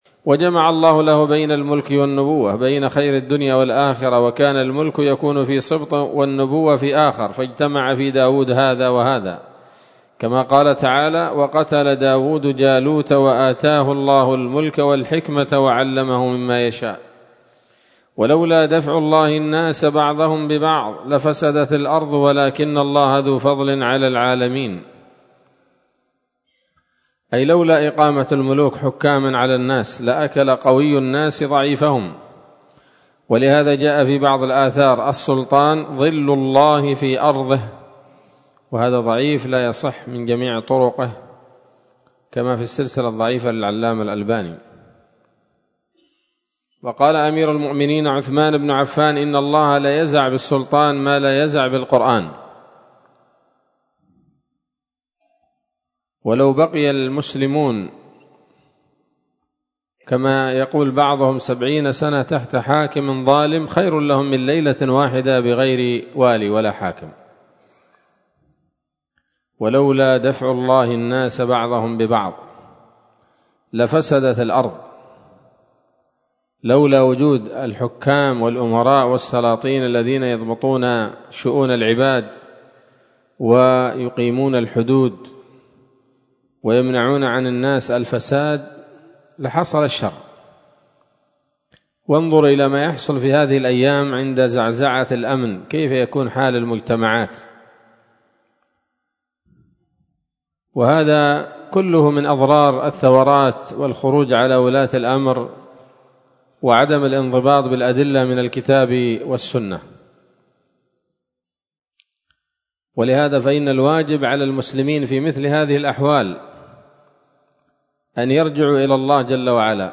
كلمة قيمة بعنوان: (( ‌نصيحتي لأهل عدن في زمن الفتن )) ظهر الأربعاء 18 من شهر رجب 1447هـ، بدار الحديث السلفية بصلاح الدين